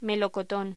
Locución: Melocotón
voz